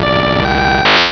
pokeemerald / sound / direct_sound_samples / cries / articuno.aif
articuno.aif